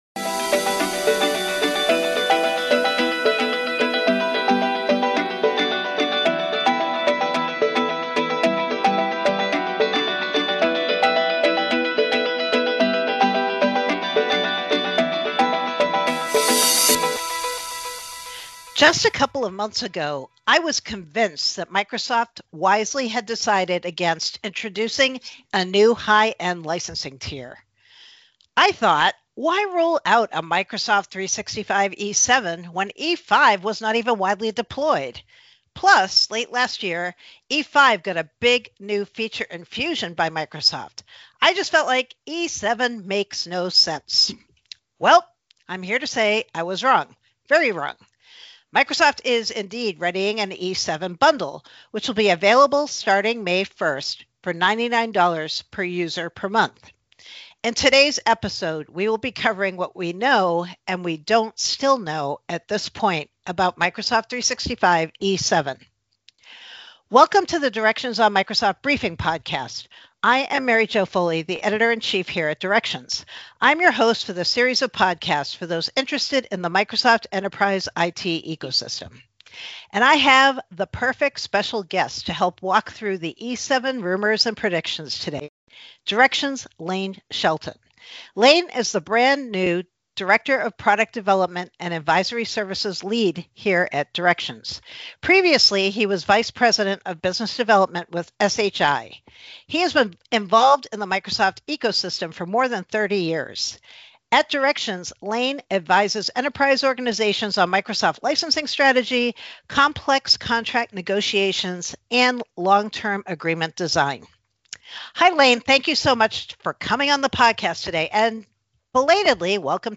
This week on the GeekWire Podcast: We hit the road for a driving tour of the week’s news, making stops at Starbucks, Microsoft, and an Amazon Fresh store in its final days.